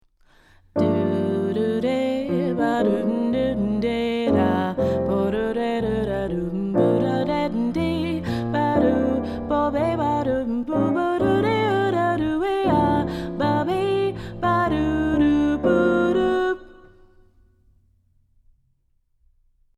Voicing: Vocal